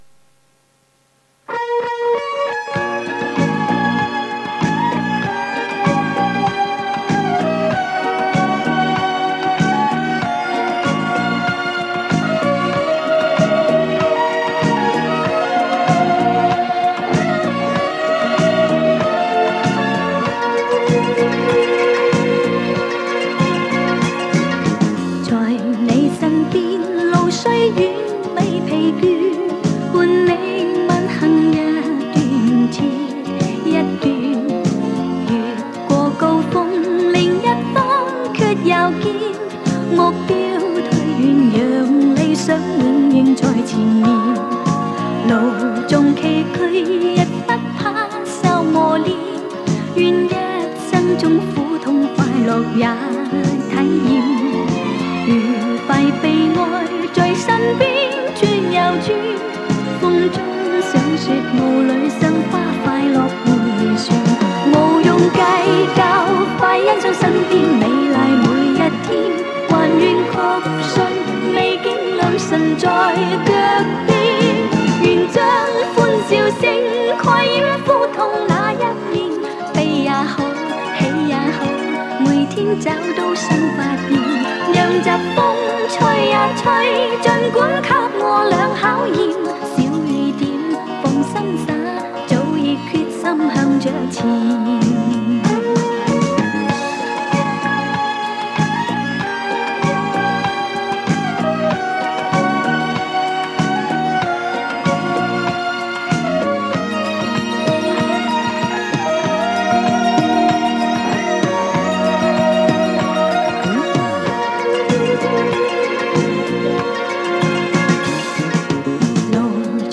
磁带数字化：2022-08-06